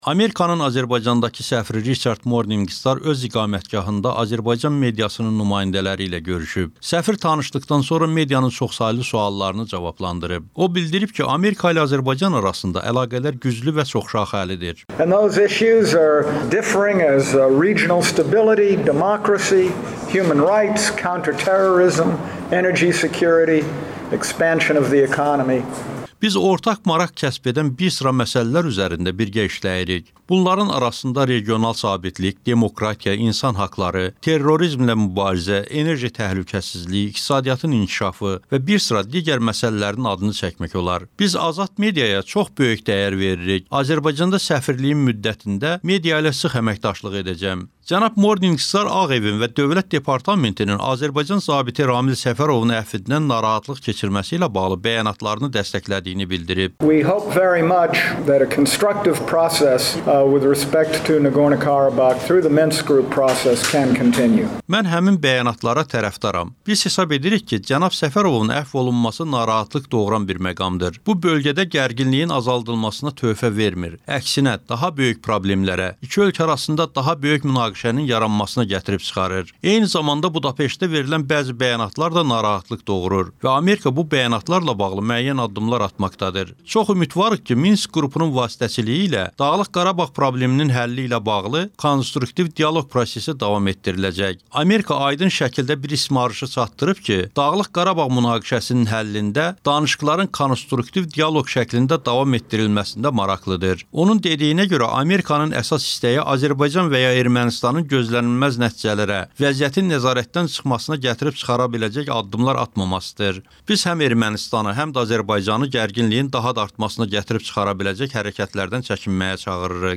Amerikanın Azərbaycandakı səfiri Riçard Morninqstarın media nümayəndələri ilə görüşü
"Amerika səfirinin dedikləri" reportajı